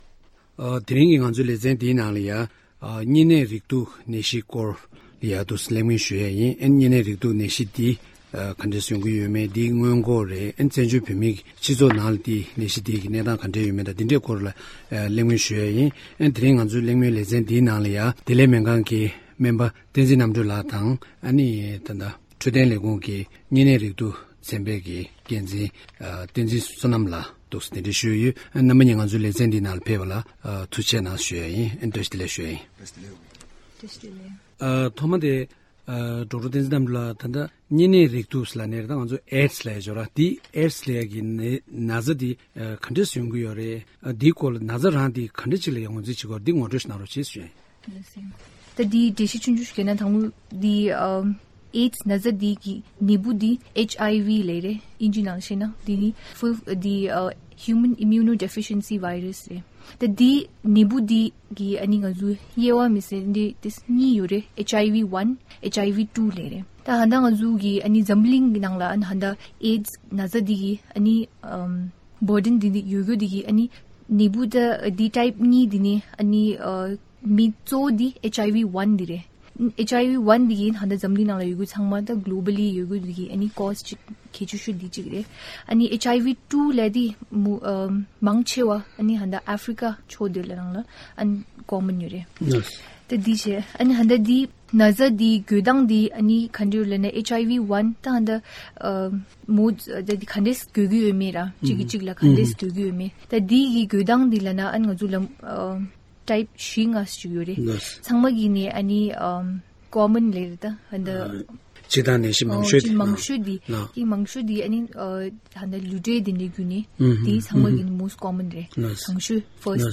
གཉན་ནད་རེག་དུག་ནད་གཞིའི་སྐོར་གླེང་མོལ་གནང་བའི་ལེ་ཚན།